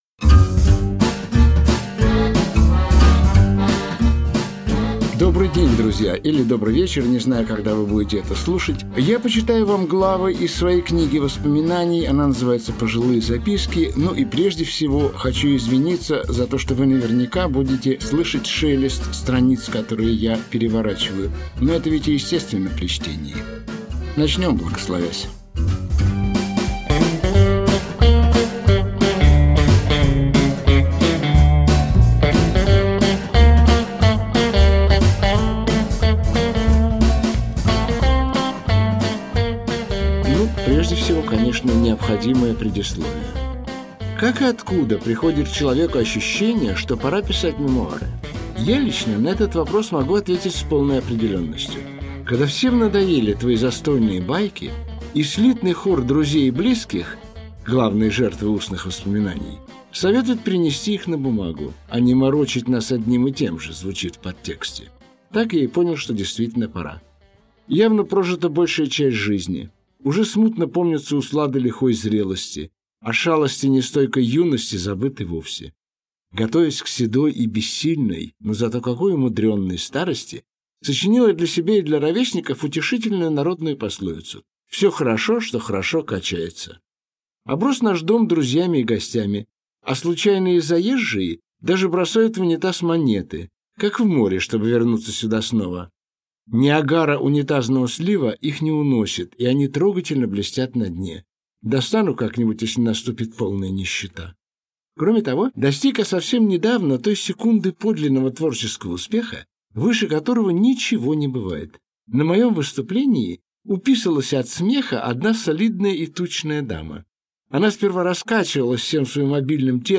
Аудиокнига Пожилые записки | Библиотека аудиокниг
Aудиокнига Пожилые записки Автор Игорь Губерман Читает аудиокнигу Игорь Губерман.